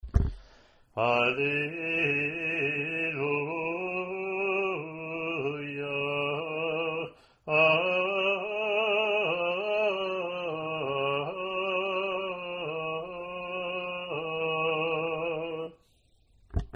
Alleluia